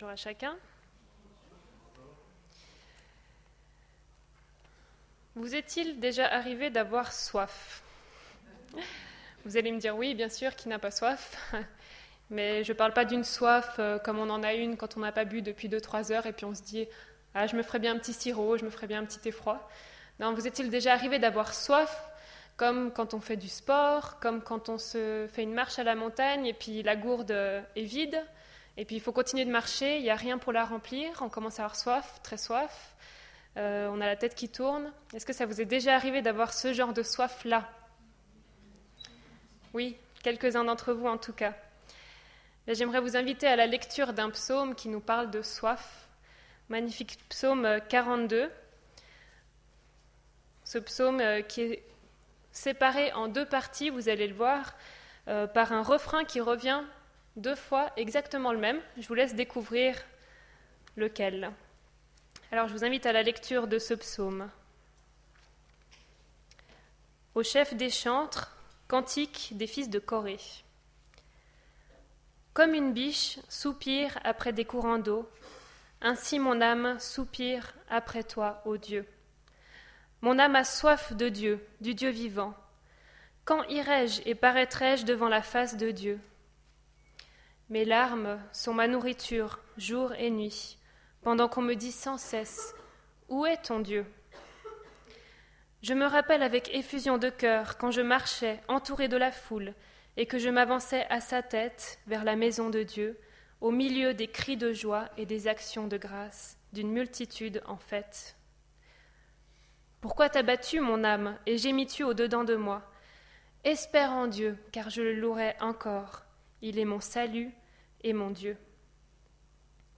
Culte du 20 septembre 2015